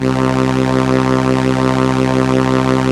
Index of /90_sSampleCDs/Keyboards of The 60's and 70's - CD1/STR_ARP Strings/STR_ARP Solina